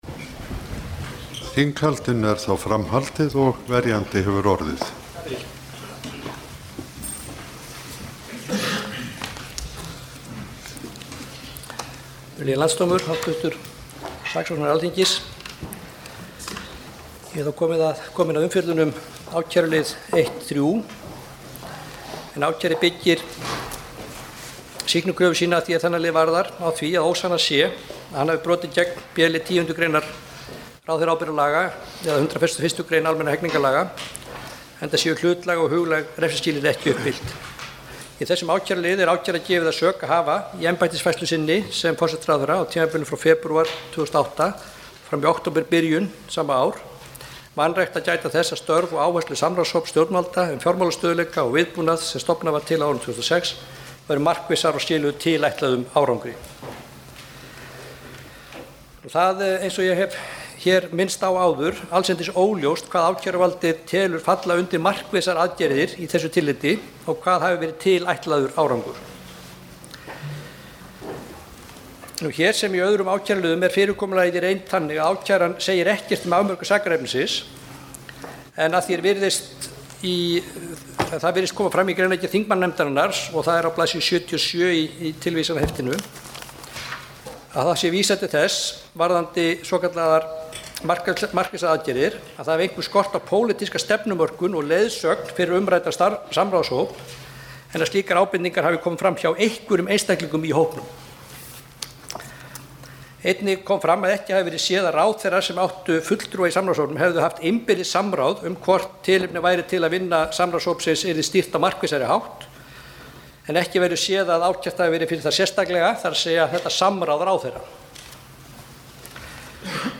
Munnlegur málflutningur föstudaginn 16. mars kl. 09:00 Hljóðupptaka Hljóðupptaka Hljóðupptaka Andmæli föstudaginn 16. mars